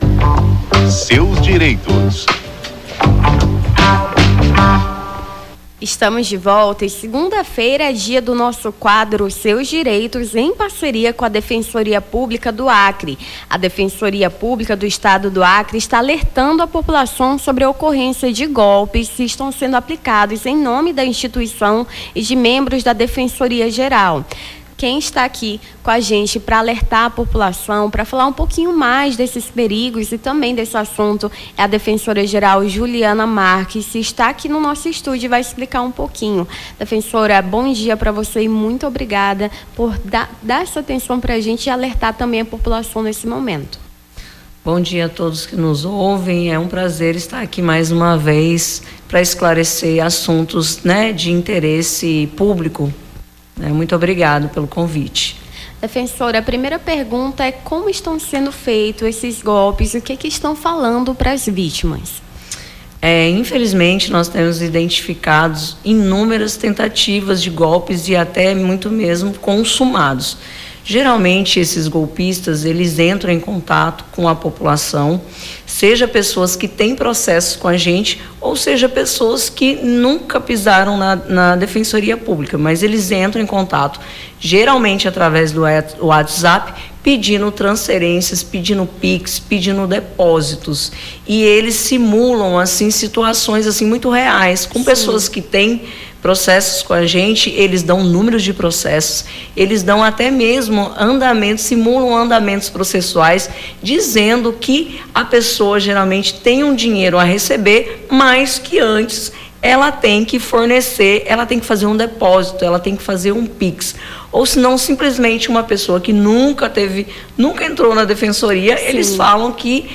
conversou com a defensora-geral Juliana Marques sobre como identificar tentativas de golpe.